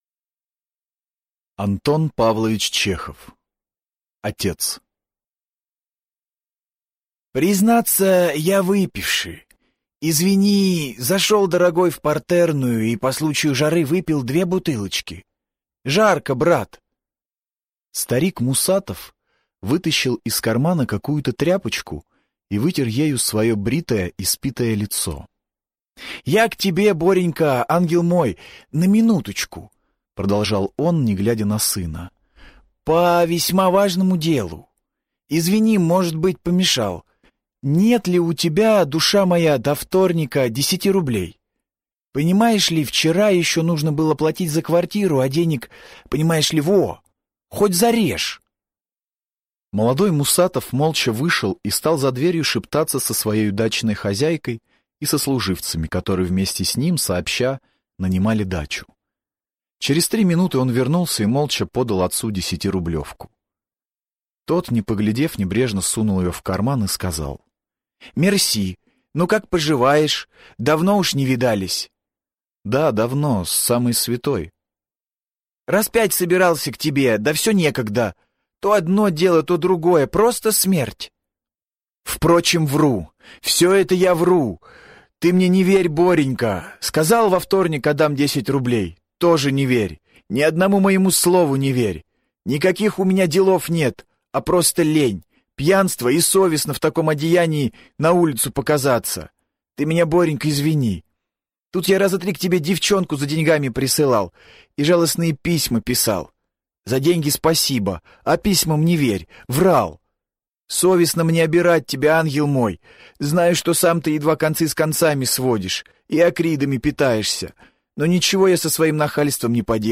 Аудиокнига Отец | Библиотека аудиокниг
Прослушать и бесплатно скачать фрагмент аудиокниги